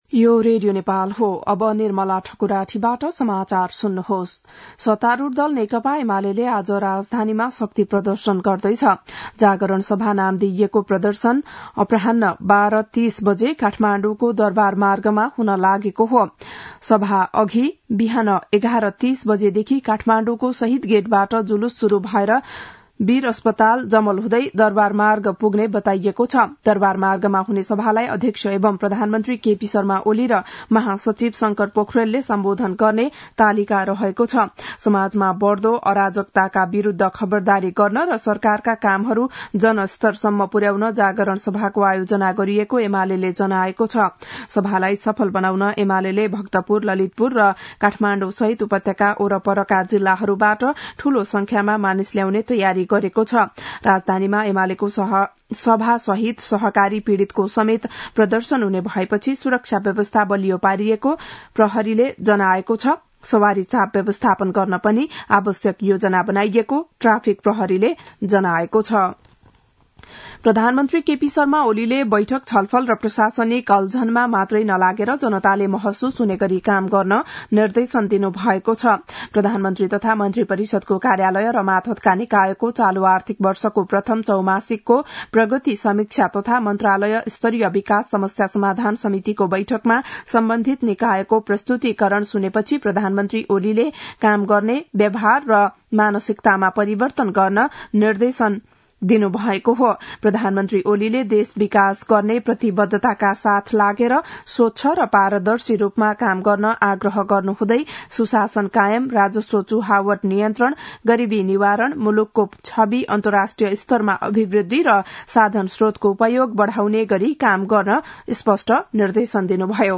बिहान ११ बजेको नेपाली समाचार : ८ मंसिर , २०८१
11-am-nepali-news-1-7.mp3